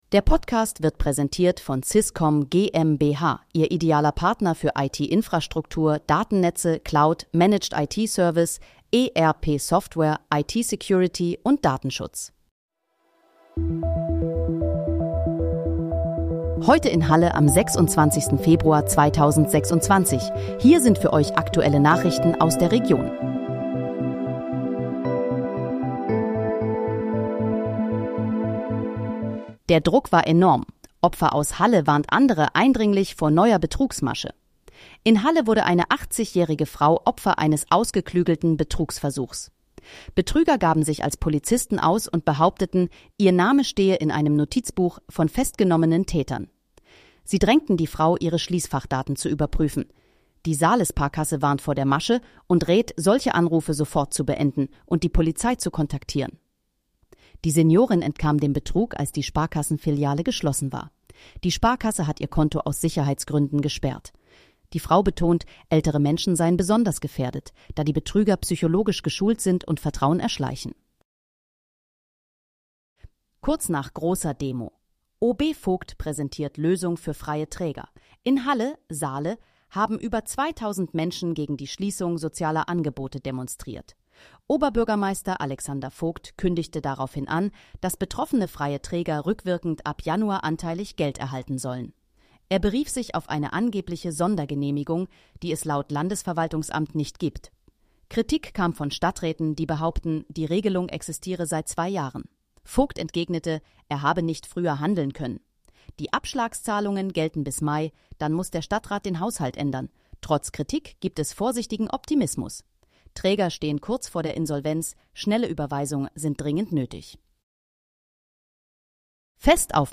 Heute in, Halle: Aktuelle Nachrichten vom 26.02.2026, erstellt mit KI-Unterstützung